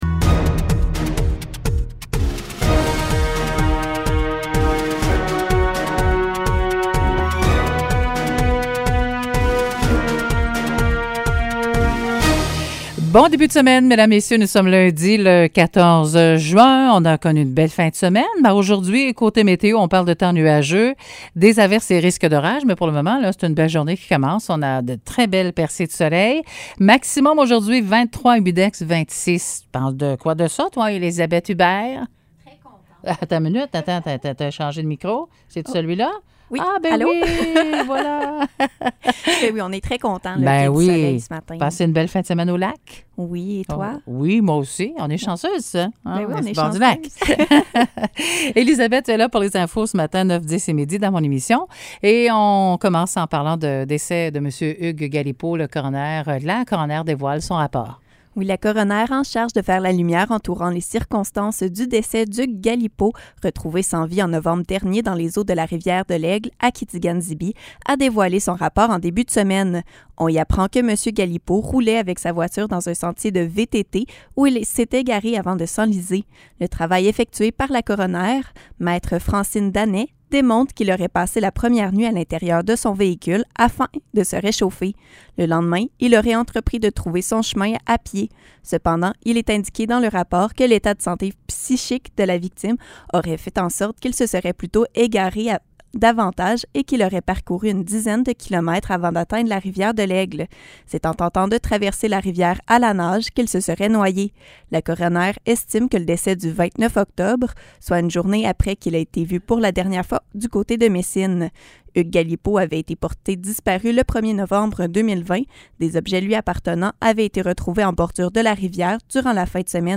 Nouvelles locales - 14 juin 2021 - 9 h